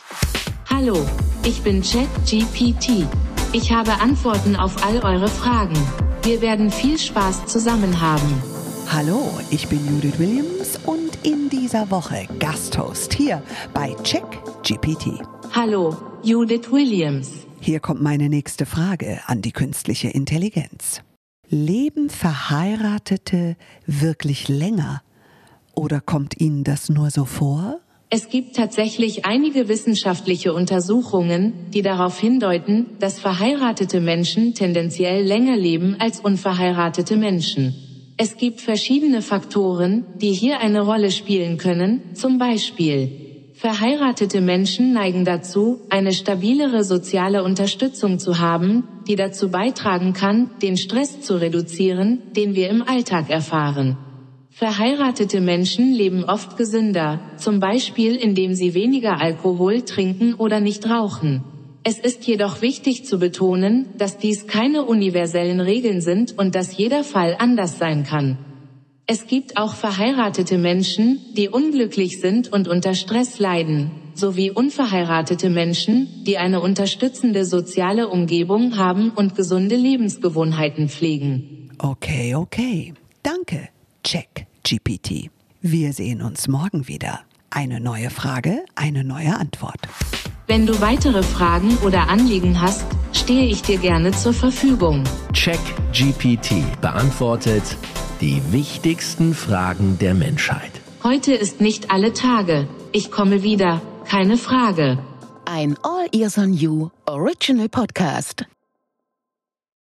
Judith Williams & KI
Hier im Podcast gibt CheckGPT die Antwort.